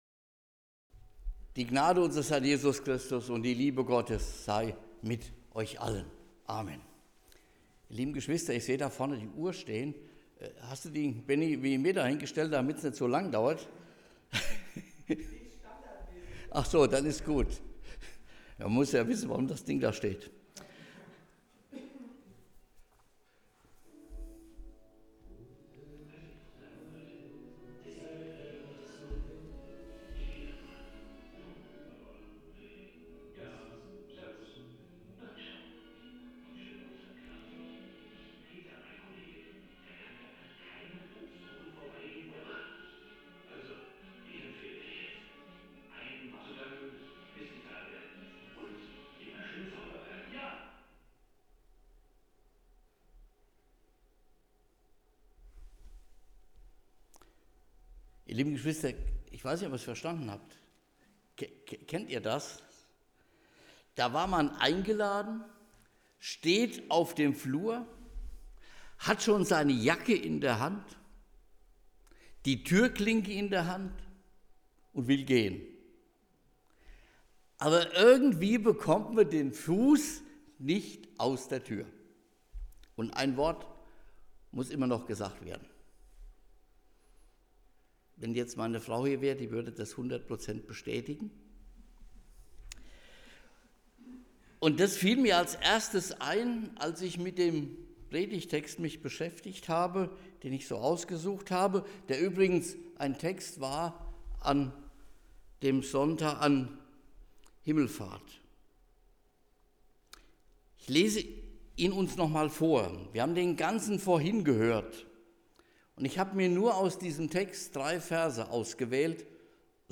Eröffnungsgottesdienst der Bibeltage 2025